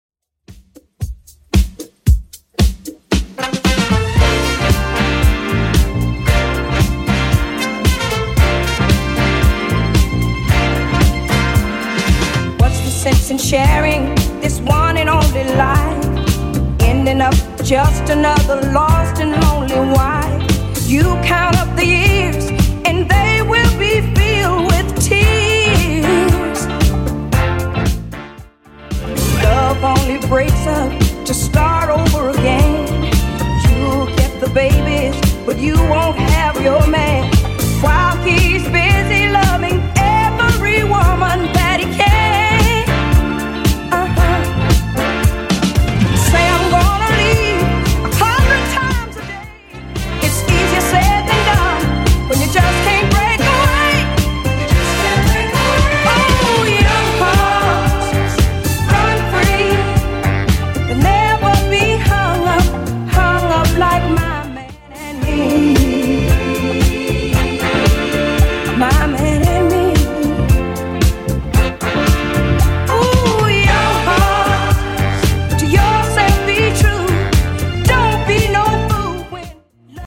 BPM: 114 Time